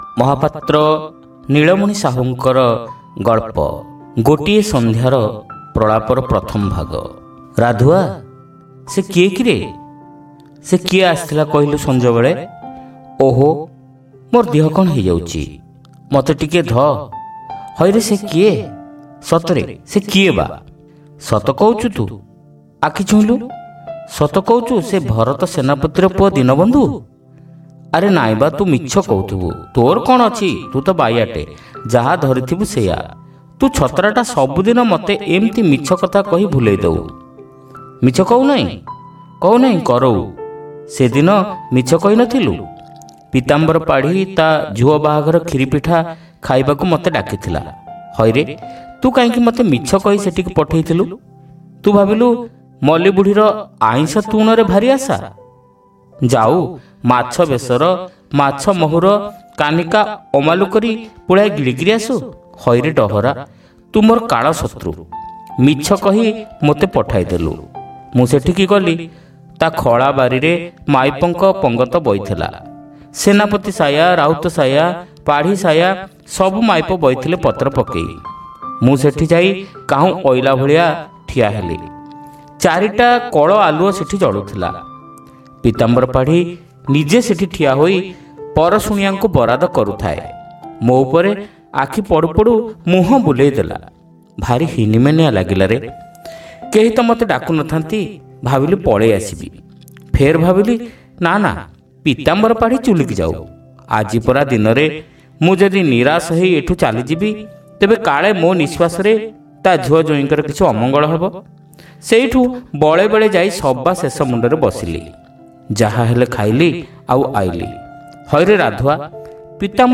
Audio Story : Gotie Sandhyara Pralapa (Part-1)